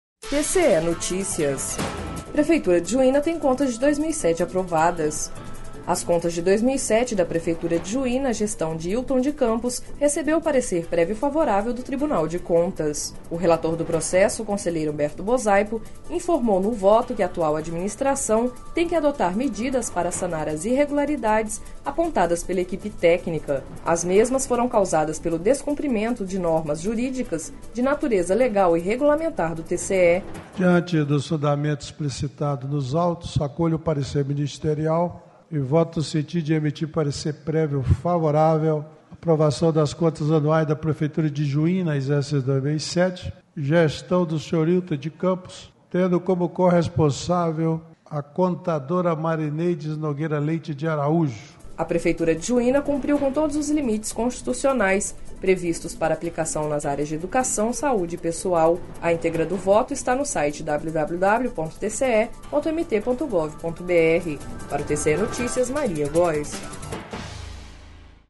Sonora: Humberto Bosaipo - conselheiro presidente do TCE-MT